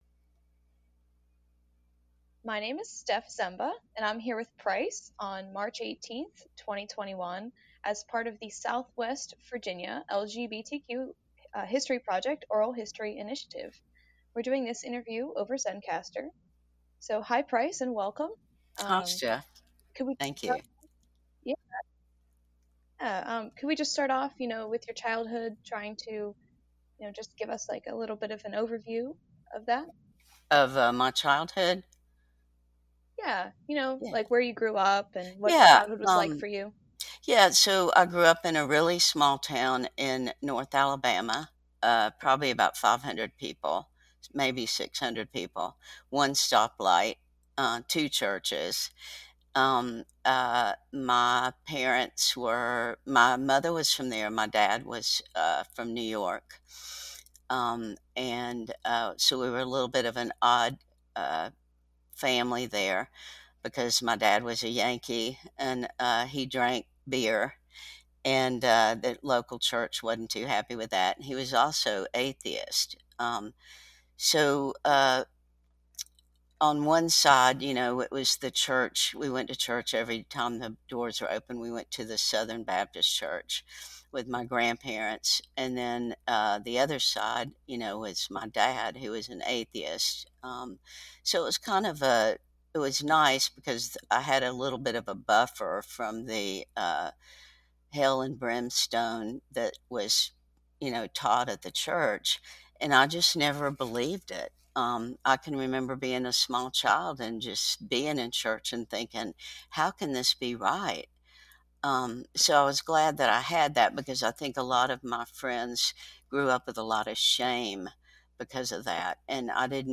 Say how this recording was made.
Location: Online via Zencastr